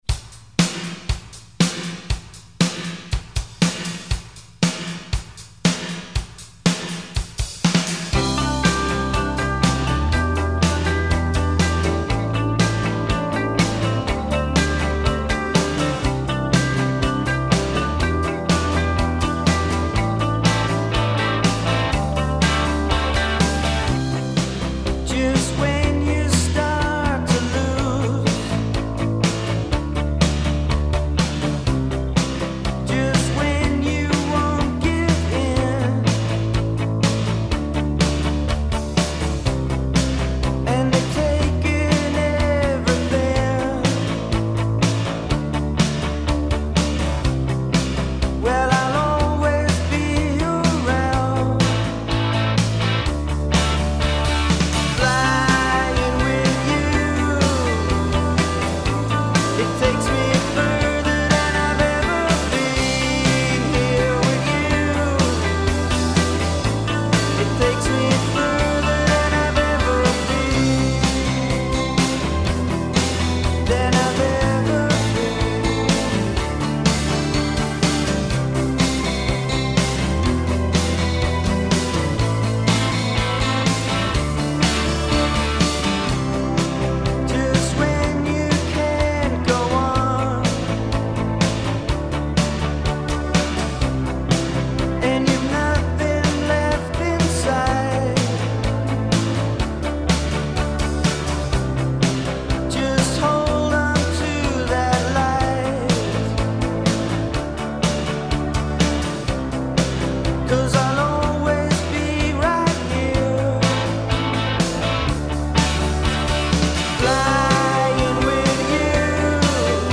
Género: Electronic.